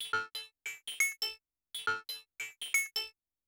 FM SEQUENC-R.wav